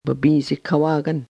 babiizikawaagan (with less nasalization) 'coat'
The last two examples, both said by the same speaker, show that there can be considerable variation in the amount of nasalization.